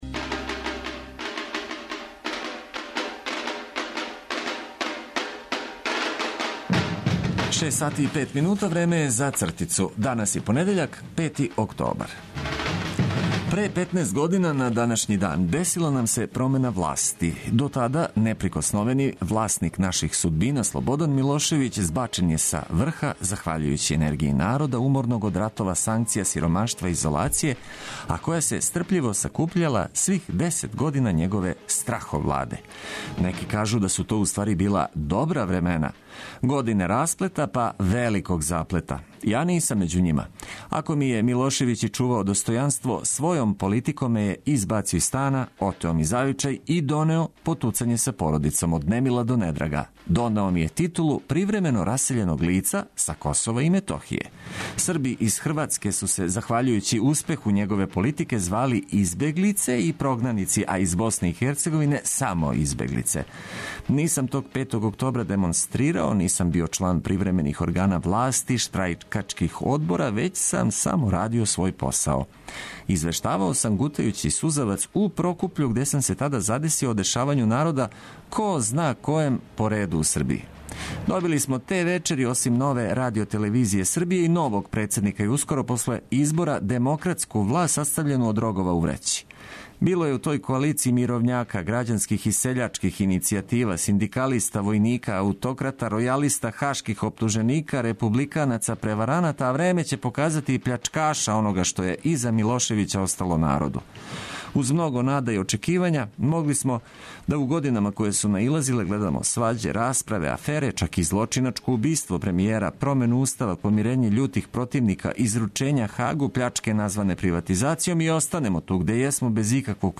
Започнимо прву радну седмицу у октобру уз осмех и ведру музику, добро расположени упркос понедељку.